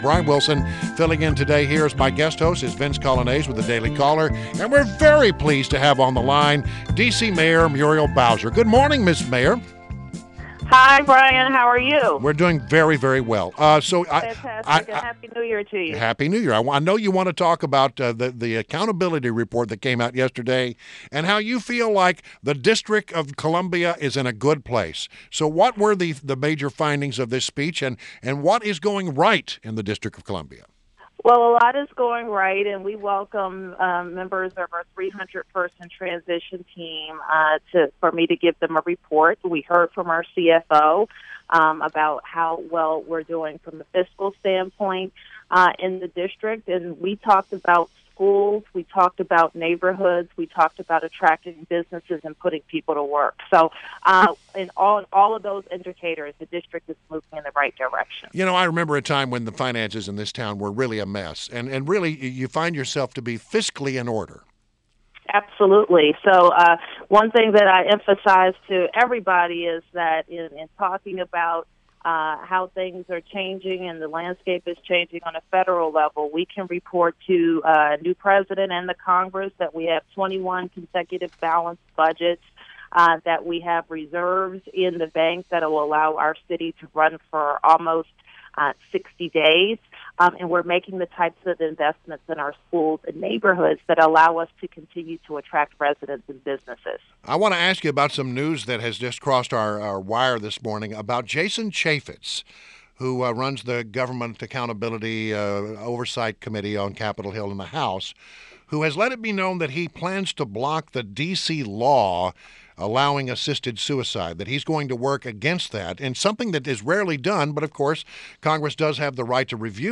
INTERVIEW - DC MAYOR MURIEL BOWSER